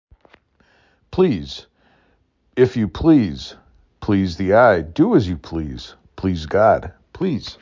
6 Letters, 1 Syllable
p l E z